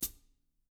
Index of /90_sSampleCDs/ILIO - Double Platinum Drums 2/Partition D/THIN A HATD